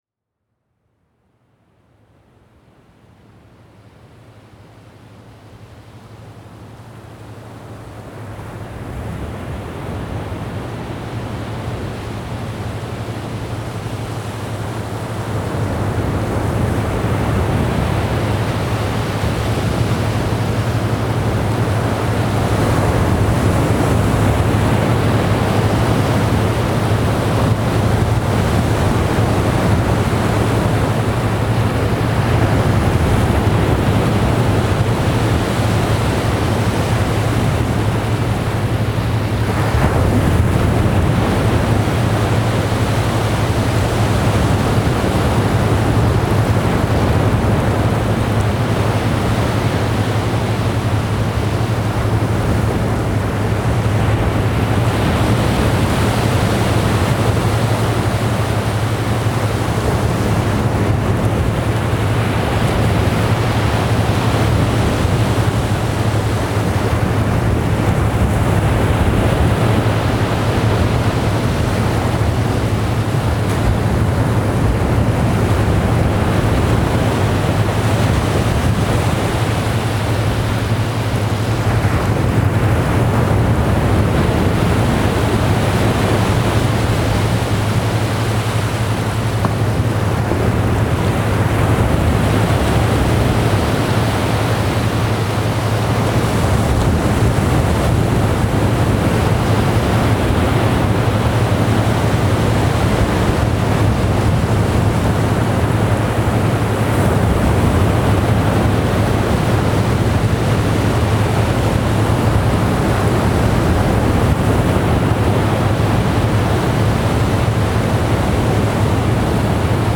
A dive into an archive of collected noises from ten megacities across the globe. Interviews and ruminations will be mixed in with these sounds, in search of a better understanding of a fundamental question: "what is noise?" On this month's program, we listen to music too fast or too slow and consider how their meanings change with speed.